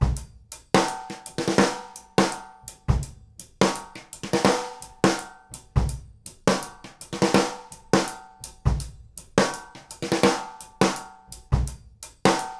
GROOVE 170GR.wav